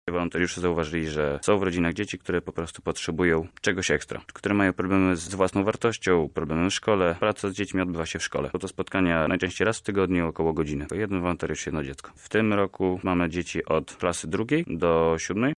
Więcej o Akademii Przyszłości mówi wolontariusz